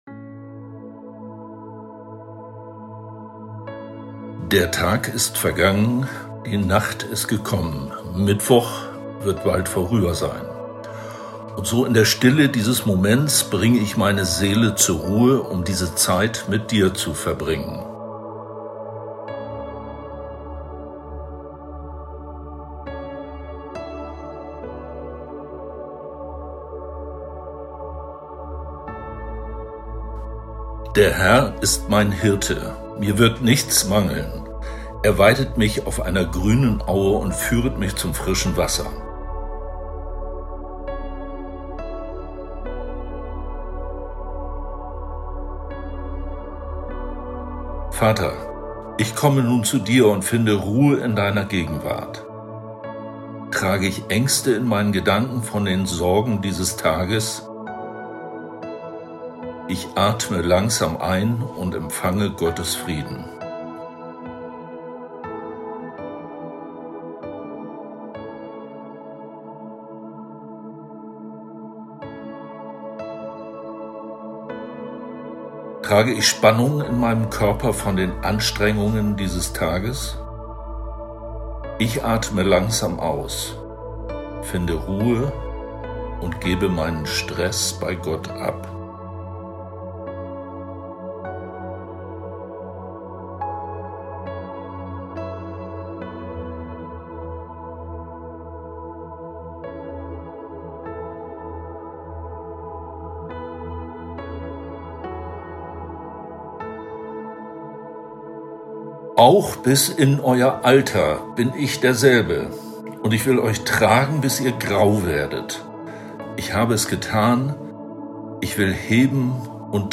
Abendmeditation am 30.08.23